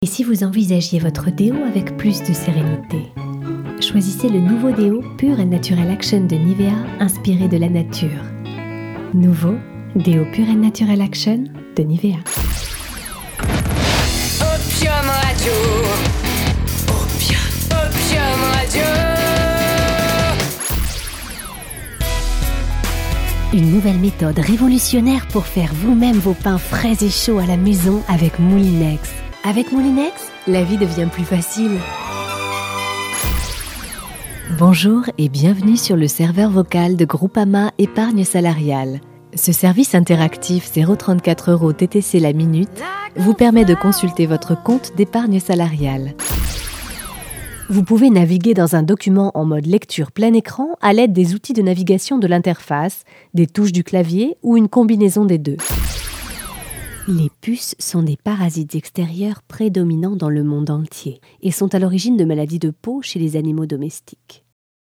Tous types de voix pour e-learning, institutionnel, promo douce.
Sprechprobe: Werbung (Muttersprache):
My voice is perfect for smooth commercials or institutional recordings.